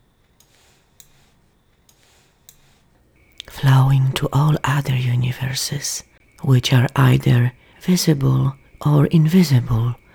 The first ones during silence are in fact, unusual.
The “standard” ones are just one hard click.
There are also few clicks underneath the words.
Please note this is a compilation not a continuous recording.
I hear the ticks clearly in the first 3 seconds of the sample. I also hear room ambience behind them and note that they have a rhythmic cadence. Almost like they came from a wind-up clock.
this second clip might help - the clicks are tiny and frequent, several underneath the voice, especially under the words: “truth” and “dimension” - sounding almost like some sort of tiny particles rubbing against each other…The cadence of the clicks over silence is incidental and somewhat misleading as it is also a compilation.
The irregular clicks, most obvious between ‘truth…about’ and ‘dimension…of’, sound like mouth noise to me.